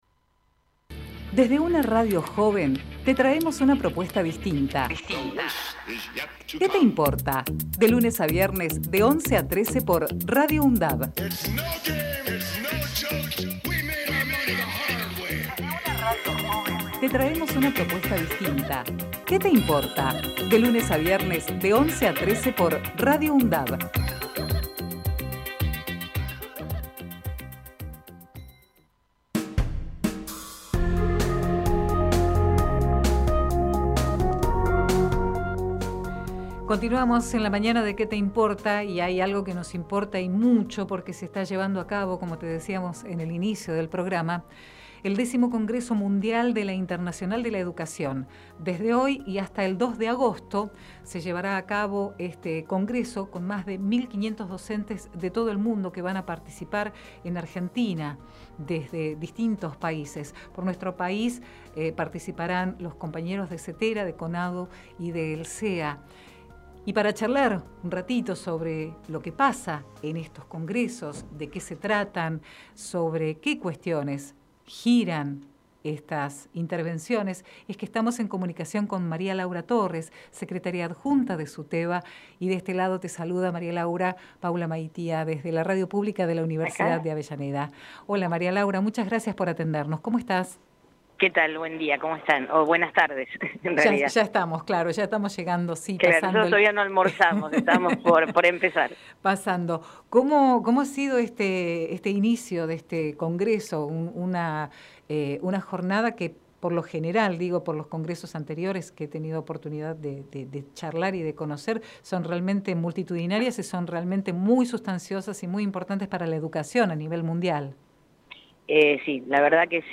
COMPARTIMOS ENTREVISTA